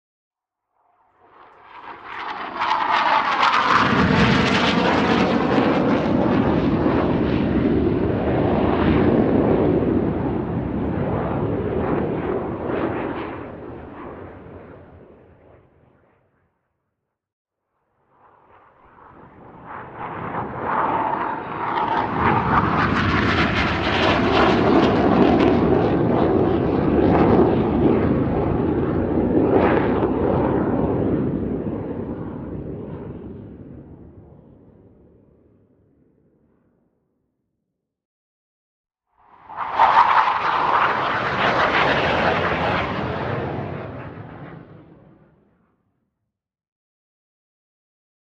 Airplane Avro Vulcan flyby with air distortion turn jet